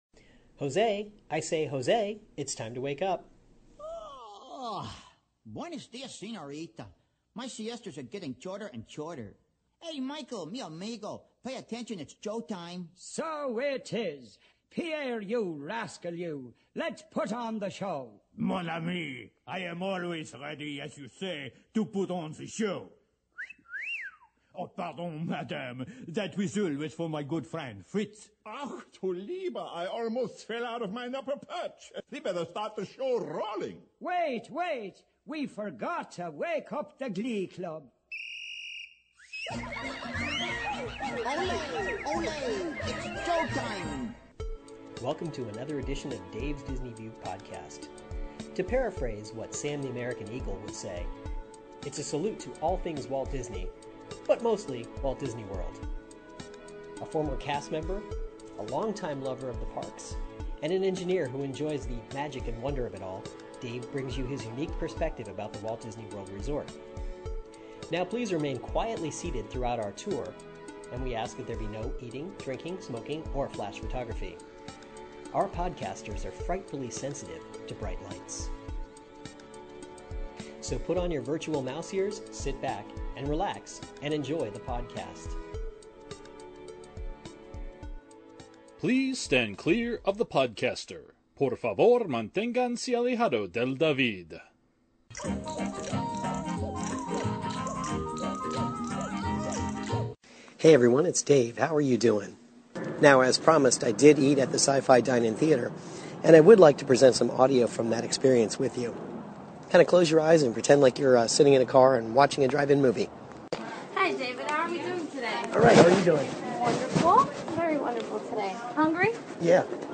I ate at the Sci-Fi Dine In Theater, and simply turned on my recorder. The audio is mostly the ambient sounds from the restaurant.